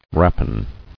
[rap·ine]